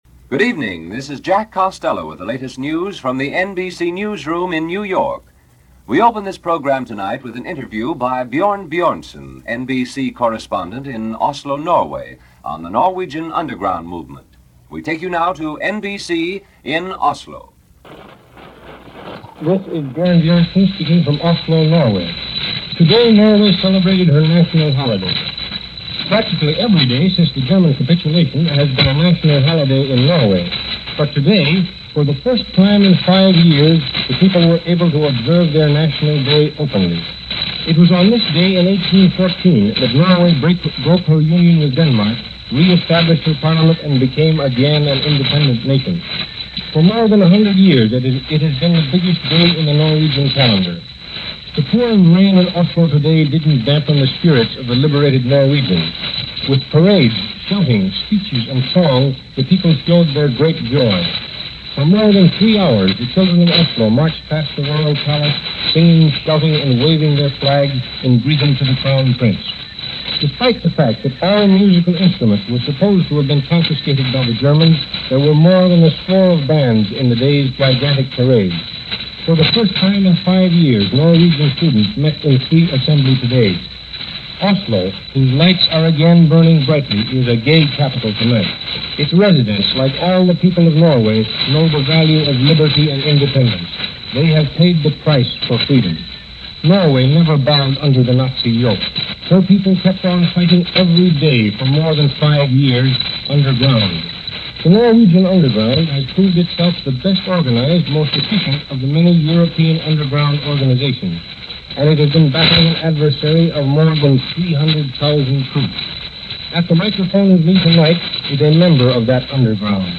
Okinawa: Seconds And Inches - Celebrating In Norway - Manhunt In Germany - May 17, 1945 - news for this day from NBC.